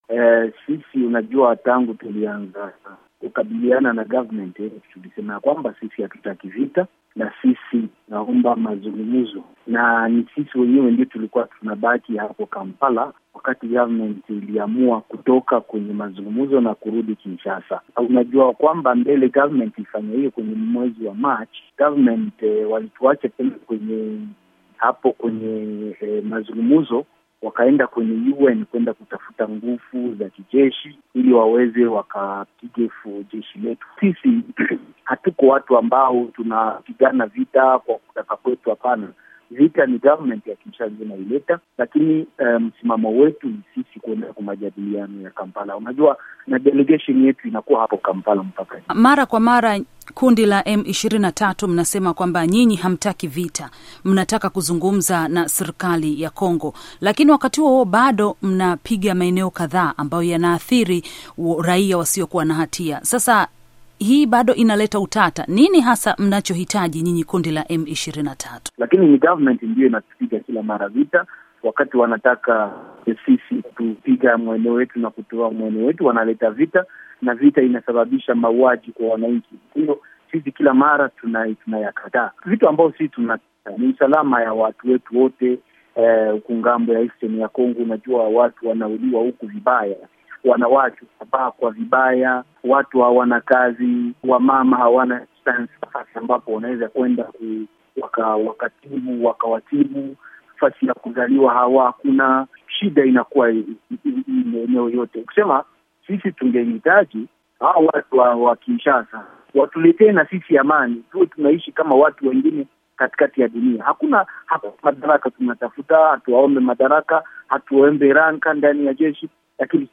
Mahojiano na Bitran Bisimwa